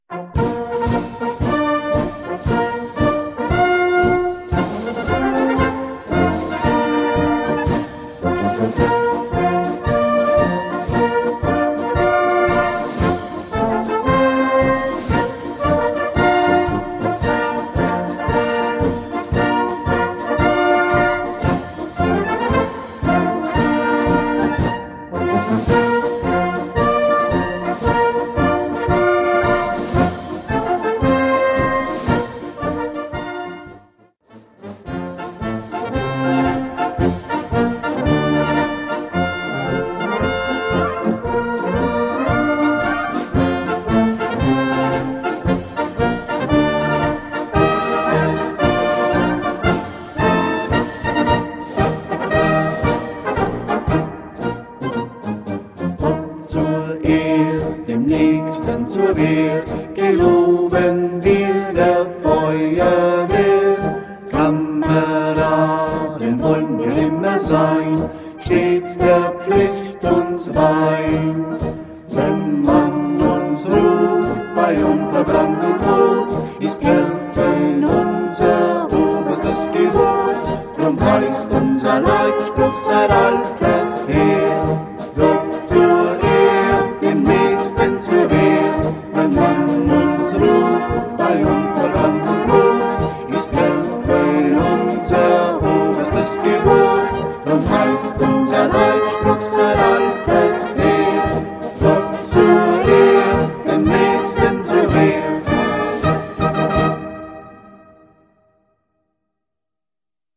Marschbuchformat